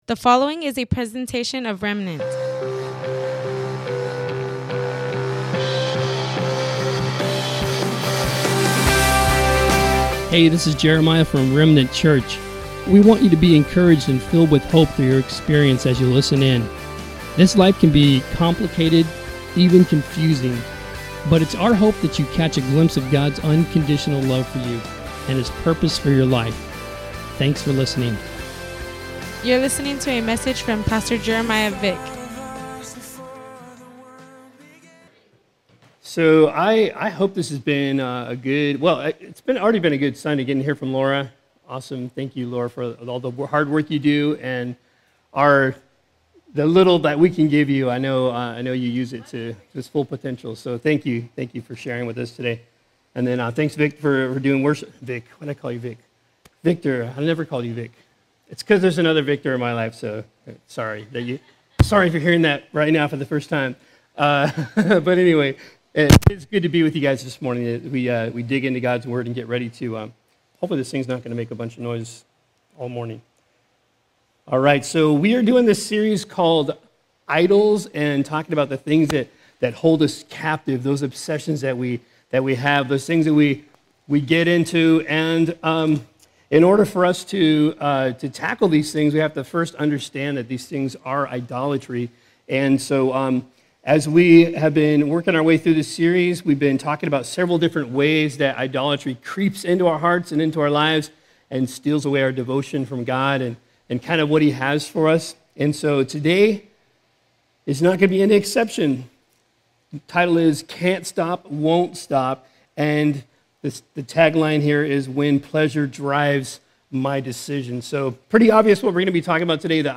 Welcome to the livestream of our worship gathering at Remnant Church in Imperial Valley, CA. Today